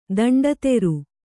♪ daṇḍa teṛu